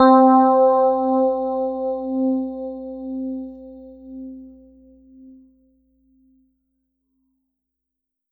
Doogie_C4.wav